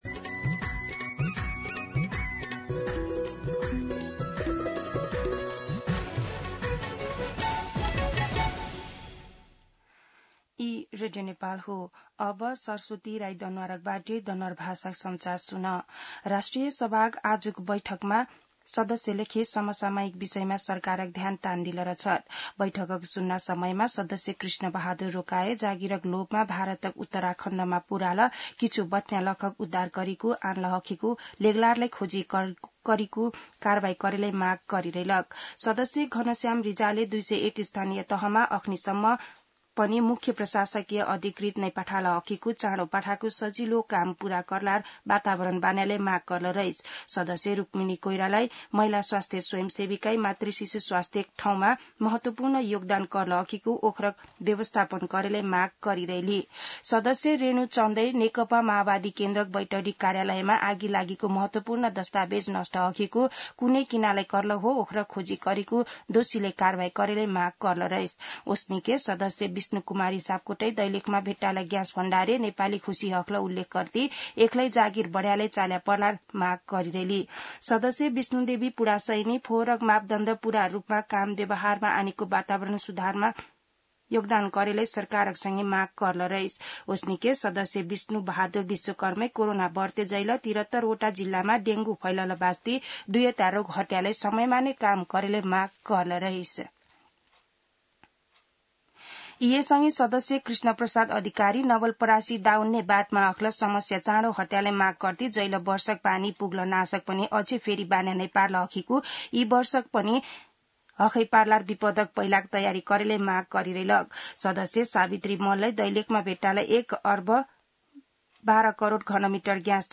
दनुवार भाषामा समाचार : १२ असार , २०८२
Danuwar-News-3-12.mp3